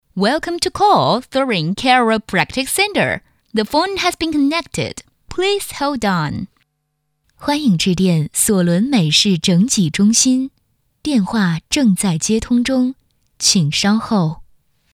【专题】索伦美式英文女45.mp3